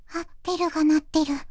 voicevox-voice-corpus / ita-corpus /中国うさぎ_こわがり /EMOTION100_017.wav